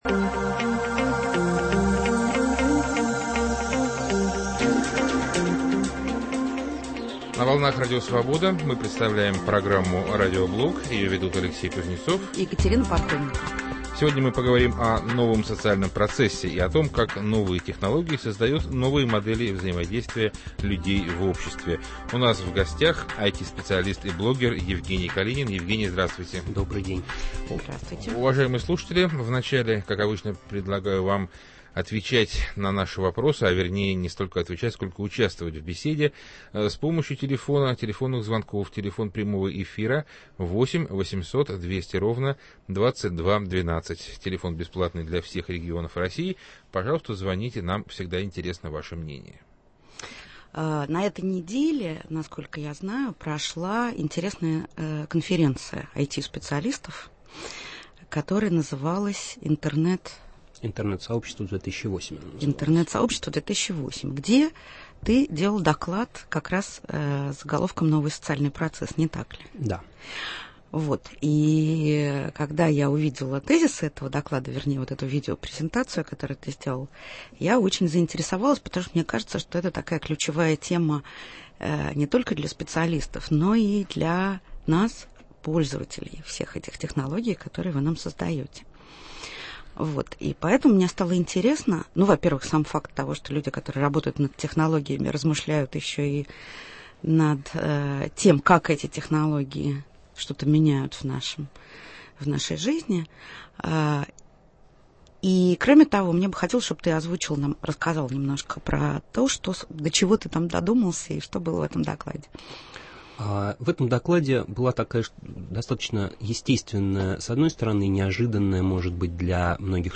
В Радиоблоге говорим о новом социальном процессе. Как новые технологии создают новые модели взаимодействия людей в обществе. Гость программы - IT-специалист и блогер